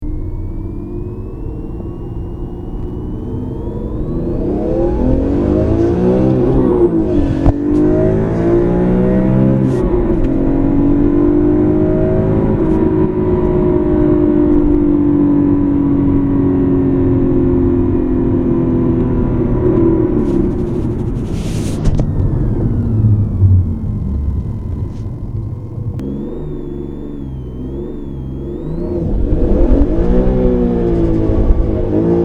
Sons de moteurs bmw - Engine sounds bmw - bruit V8 V10 bmw